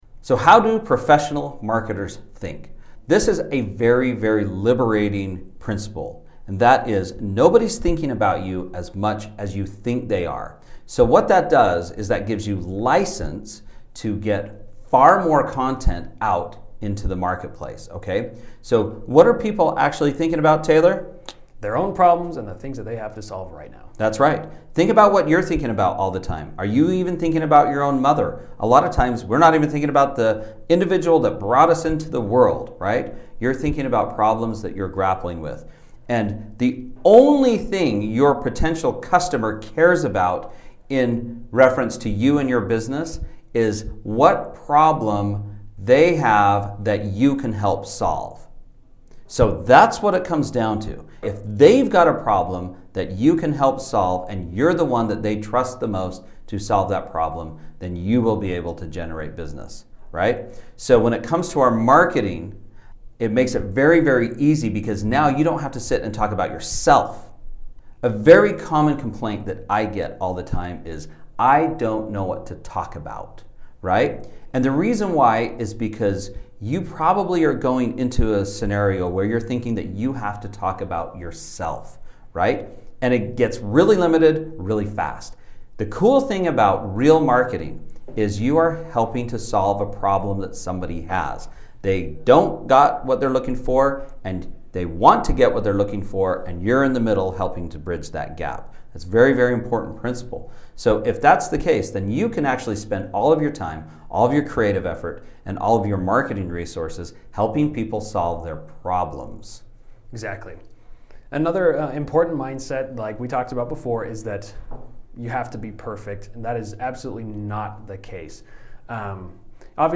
Episode Type: Mindset & Strategy